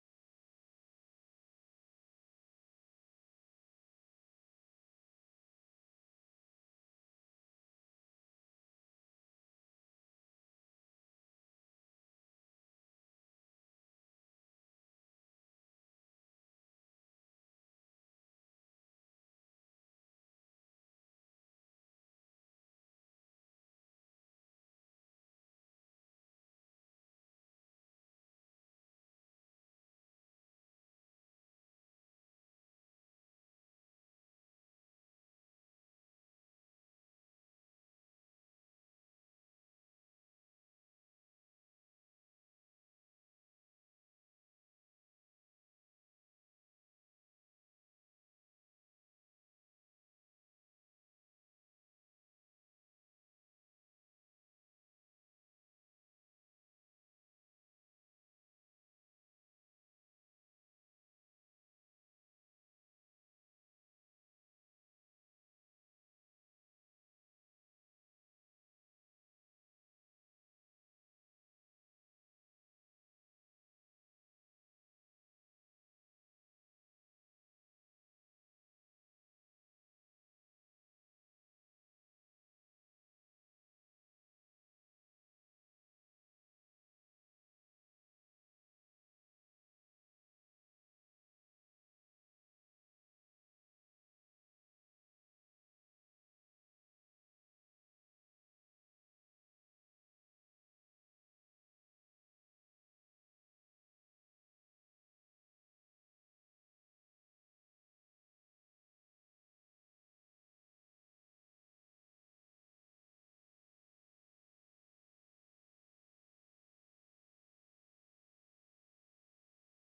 Our worship team leads us in some amazing worship.
Praise Worship